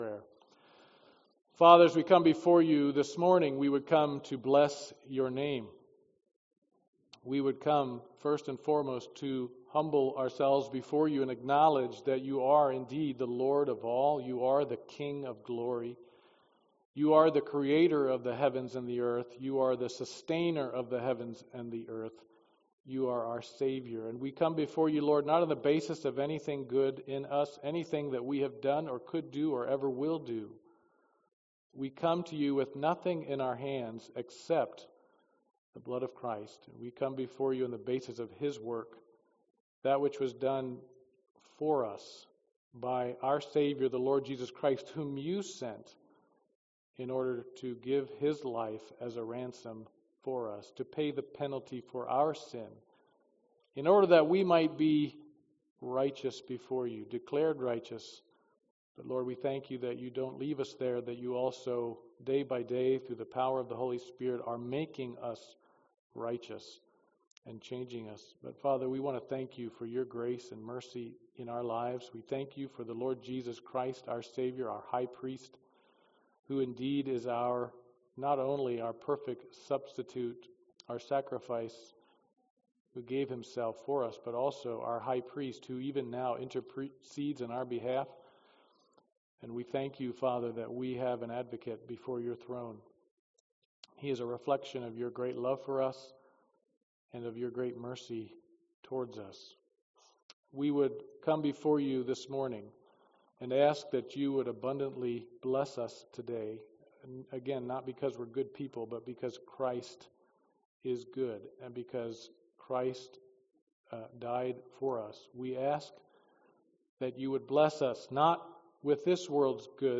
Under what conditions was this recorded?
Passage: 1 Peter Service Type: Sunday Morning Worship